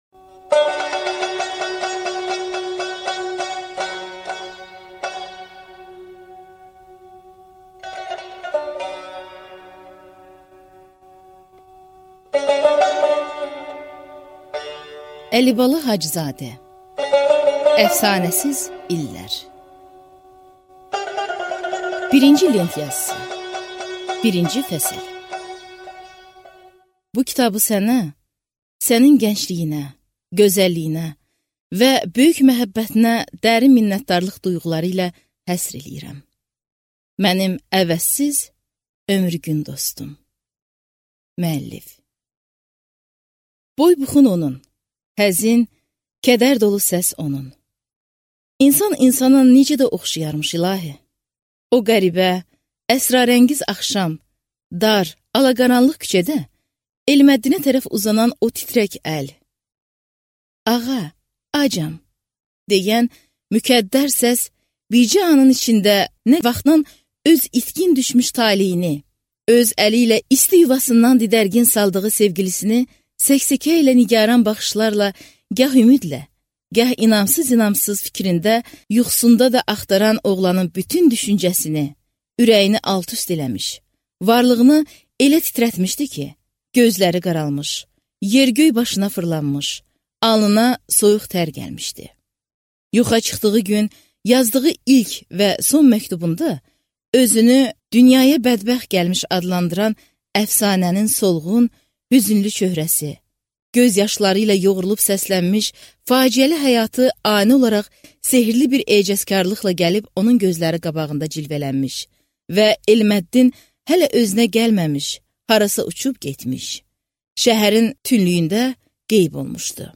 Аудиокнига Əfsanəsiz illər | Библиотека аудиокниг